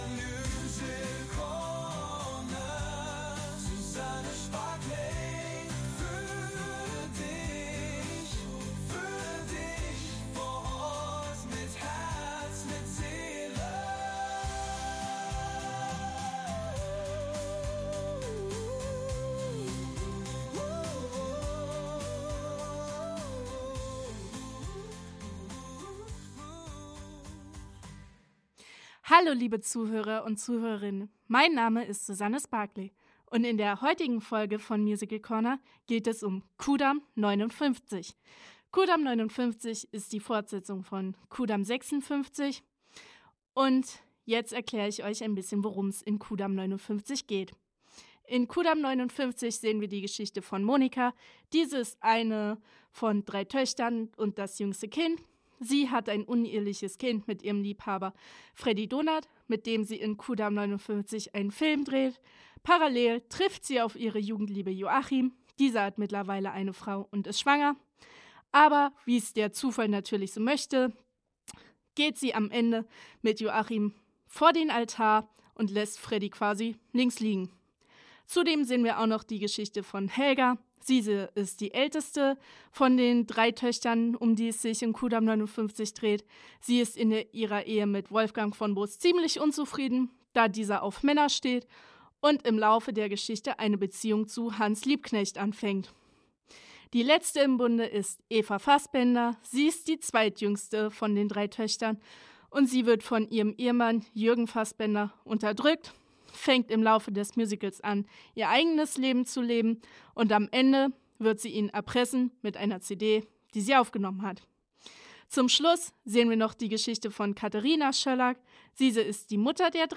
Im Gespräch sind einige Darsteller*innen zu hören.